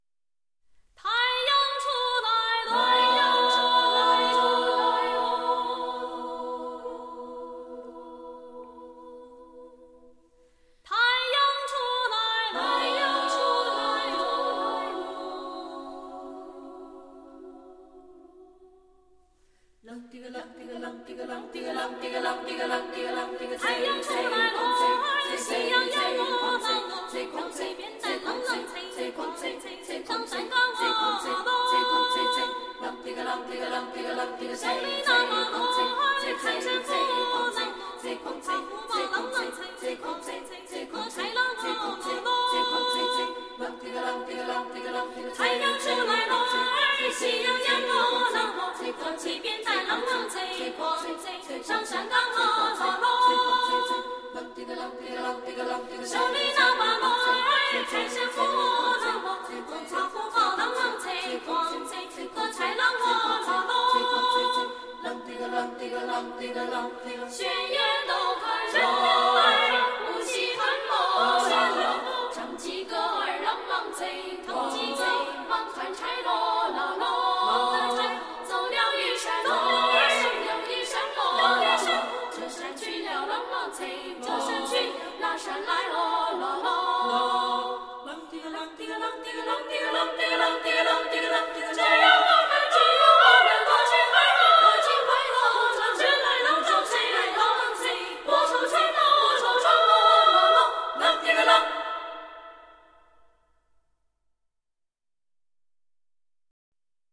乡村童趣 儿童合唱组歌
四川民歌